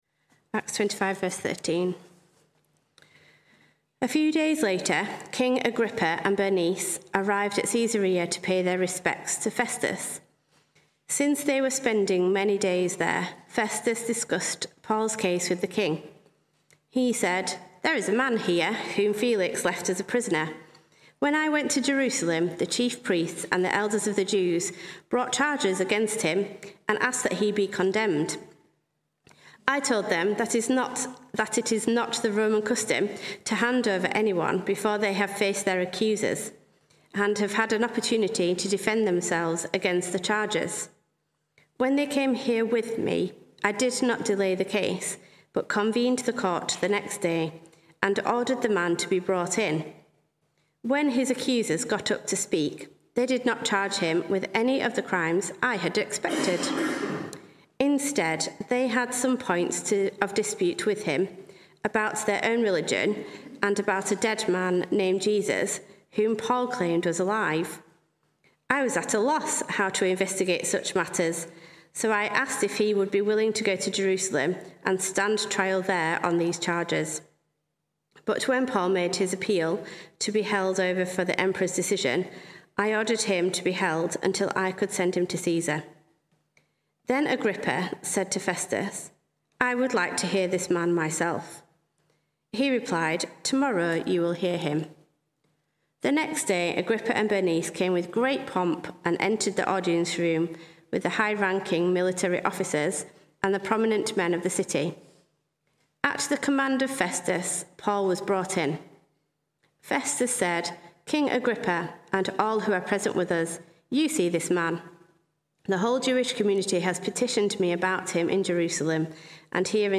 Sermons Archive - Page 19 of 187 - All Saints Preston